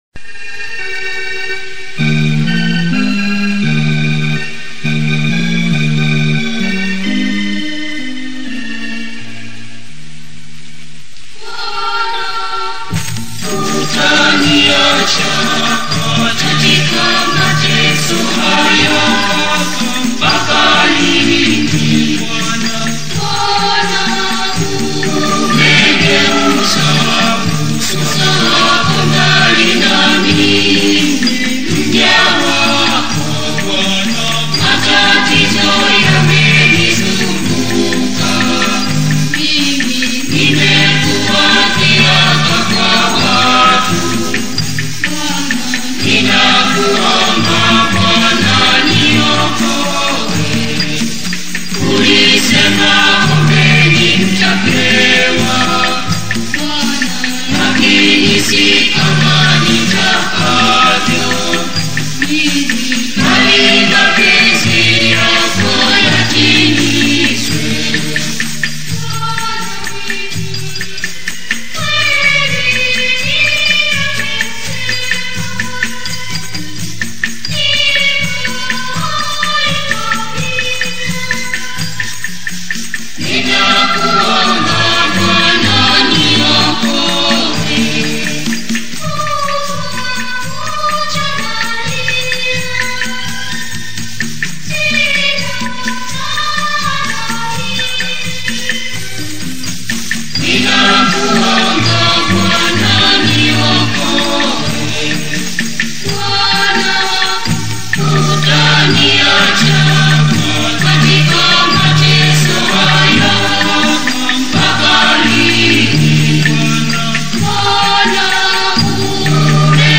gospel song